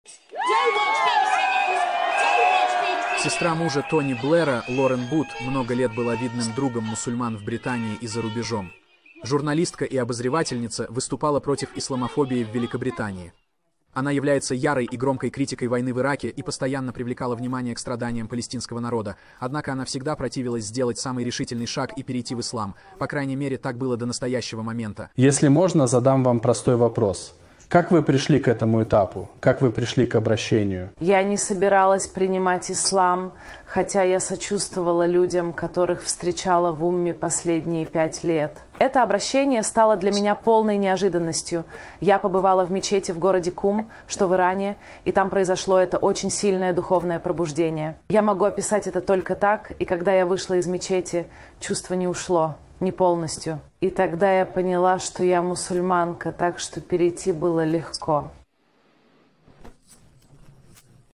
Описание: В этом видео Лорен Бут, невестка Тони Блэра, делится своей историей принятия Ислама.